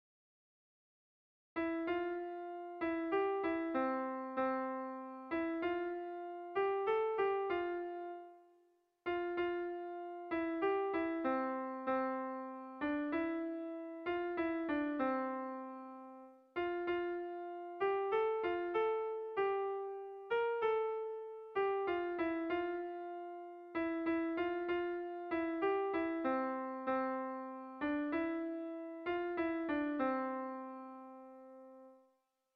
Zortziko txikia (hg) / Lau puntuko txikia (ip)
A1A2BA2